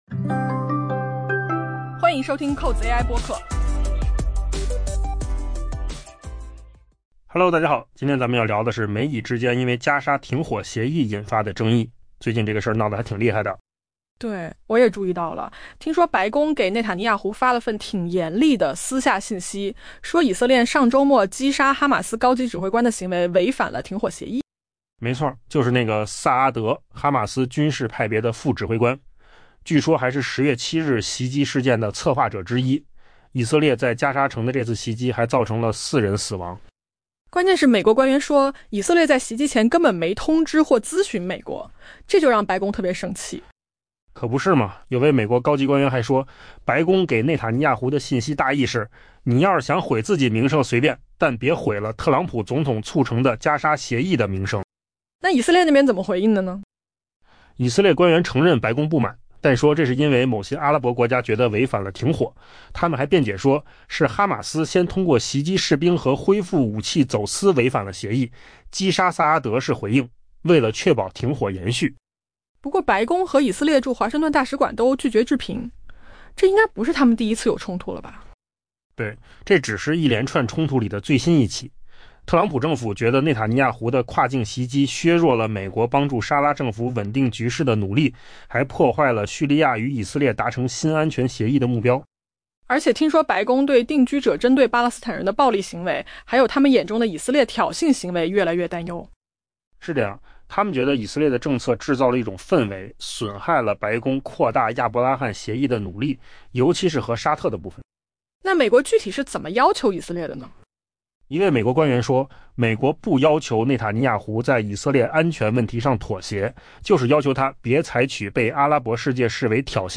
AI 播客：换个方式听新闻 下载 mp3 音频由扣子空间生成 两名美国官员告诉 Axios， 白宫向以色列总理内塔尼亚胡发出了一份严厉的私下信息，强调上周末以色列击杀哈马斯一名高级军事指挥官的行为违反了美国总统特朗普促成的停火协议。